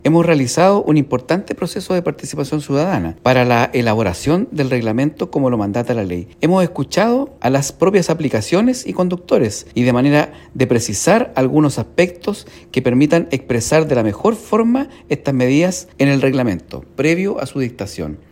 En respuesta a las críticas, el ministro de Transportes (s), Jorge Daza, aseguró que es la propia ley la que indica las exigencias técnicas y tecnológicas de la seguridad mínima con la que deben contar los vehículos.